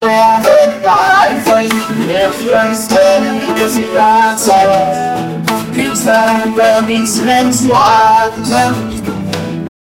musica-italiana-izkk75vi.wav